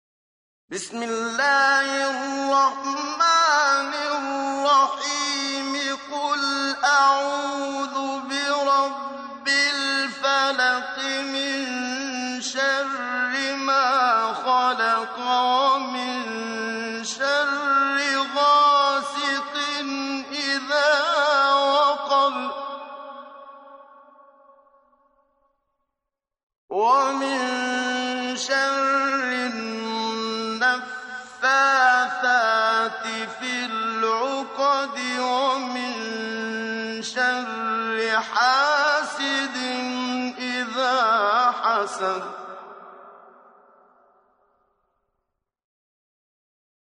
lecture mp3 Tajwîd (pour une bonne prononciation) (qdlfm) - 275 ko ;
(forme de récitation entre la vitesse normale du parler ["Hadr"] et la vitesse lente pour lire et réfléchir ["tartîl"] qui permet de connaître les règles de récitation coranique, l'une des trois branches de la Science de la récitation coranique ["‘ilm al-qirâ‘a"])
113-Surat_Al_Falaq_(L_aube_naissante)_Tajwid.mp3